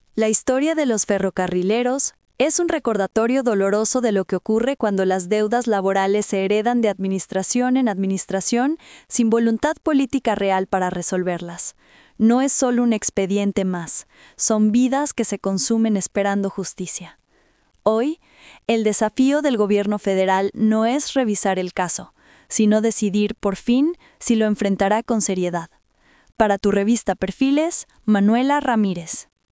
COMENTARIO EDITORIAL 🎙